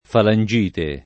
falangite [ falan J& te ]